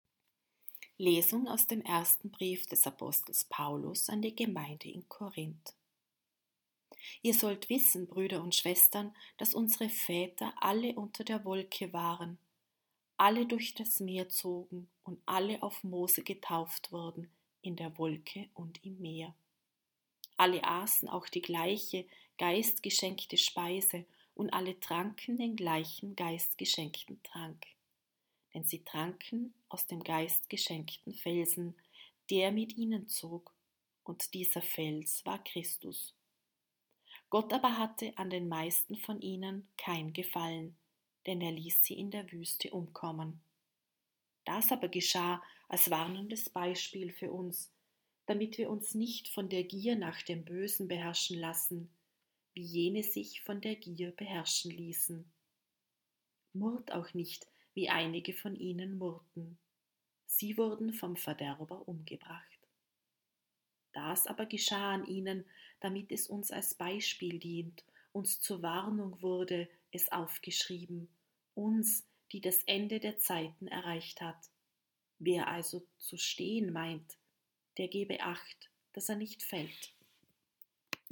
Wenn Sie den Text der 2. Lesung aus dem ersten Brief des Apostel Paulus an die Gemeinde in Korínth anhören möchten: